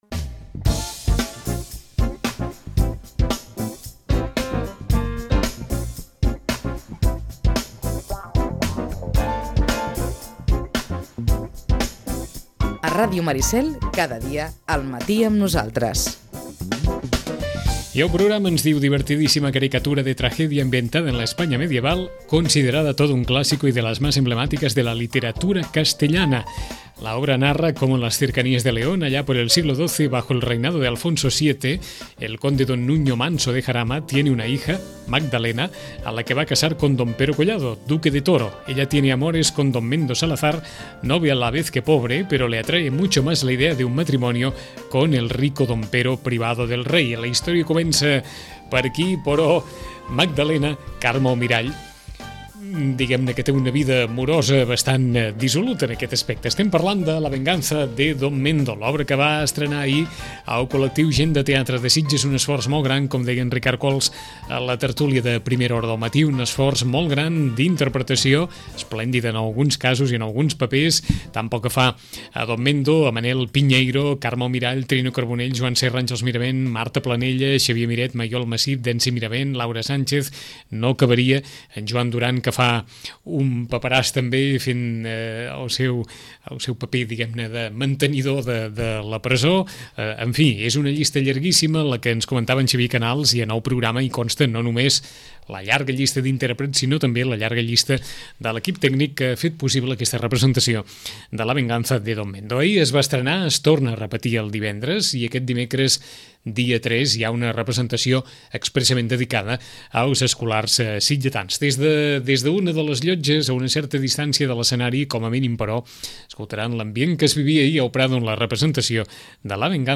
Us oferim la crònica de l’estrena al teatre Prado de La venganza de don Mendo, l’obra de Pedro Muñoz Seca que ha posat en escena el col.lectiu de la Gent de teatre de Sitges.